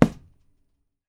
PUNCH C   -S.WAV